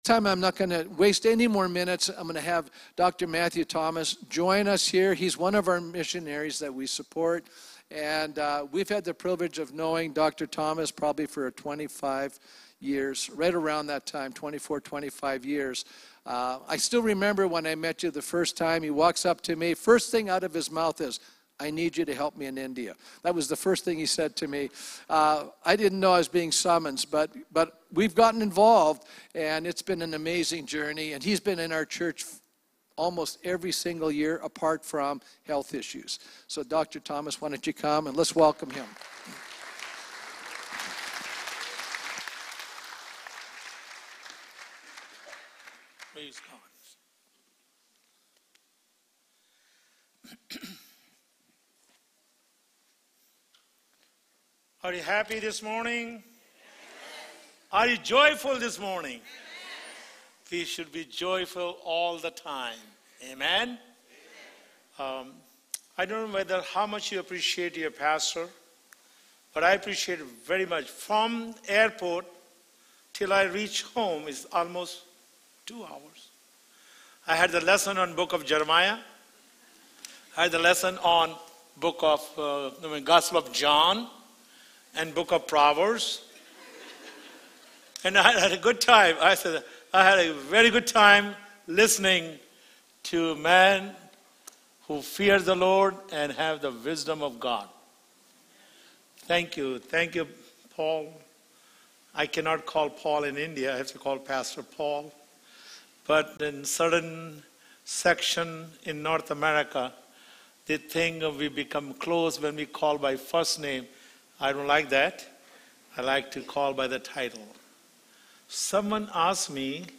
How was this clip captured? Mission Sunday